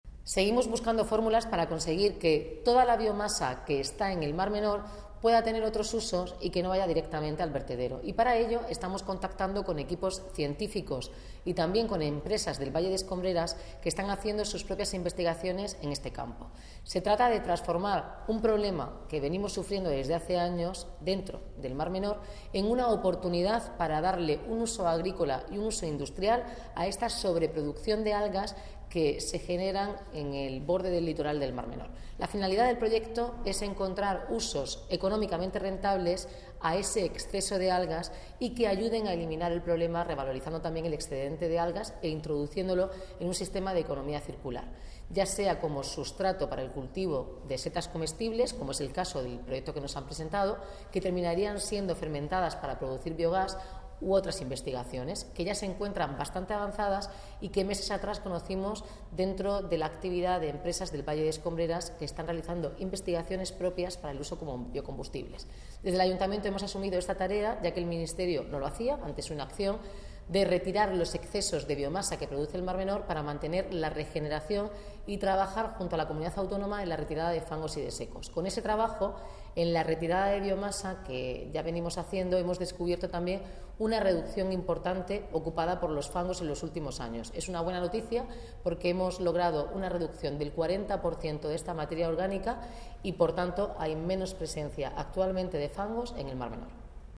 Enlace a Declaraciones de la alcaldesa Noelia Arroyo